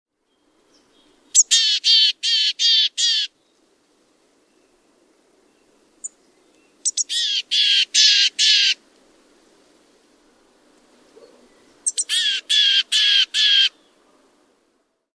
Kuuntele lapintiaisen varoitusääntä